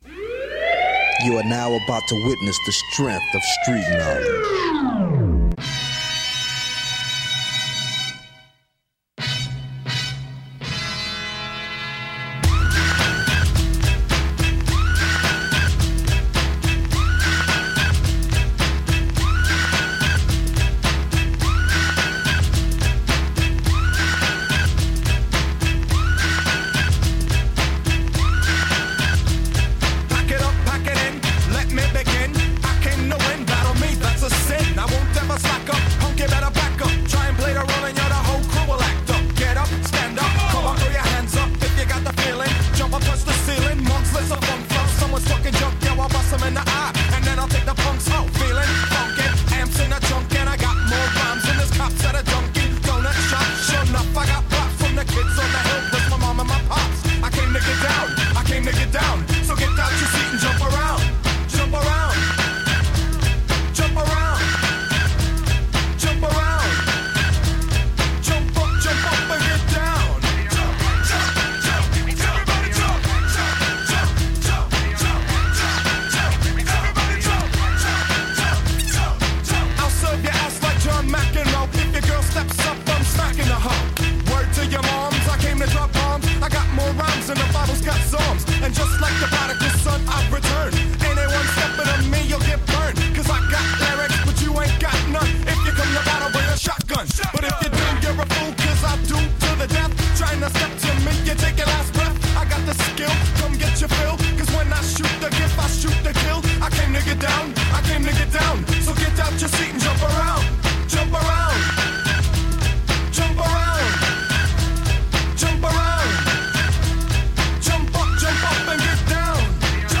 It’s a 90s special; so, we’ve got all of the 90s hip-hop classics.